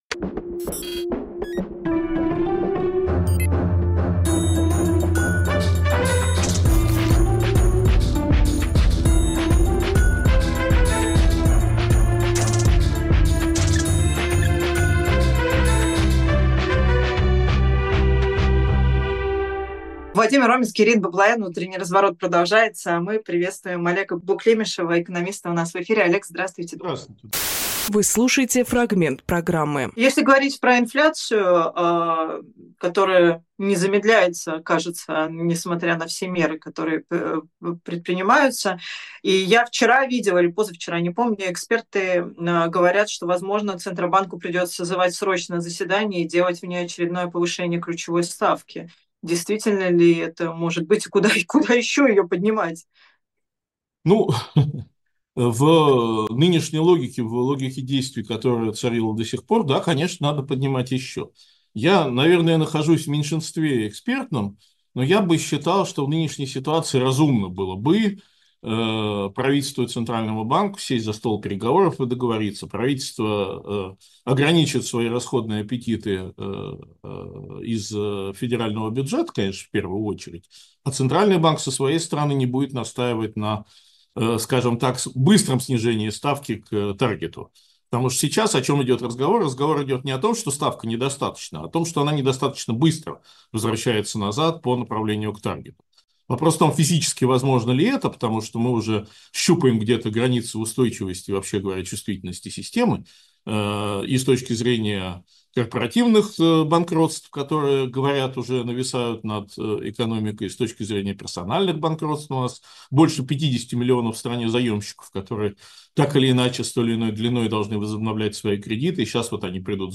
Фрагмент эфира от 25.11.24